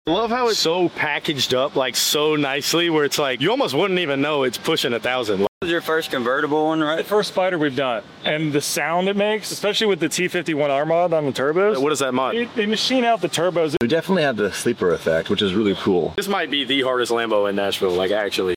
Lambo Spider_ Insane Sound & sound effects free download
Lambo Spider_ Insane Sound & T51R Turbo Mod!